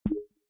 click-sound.mp3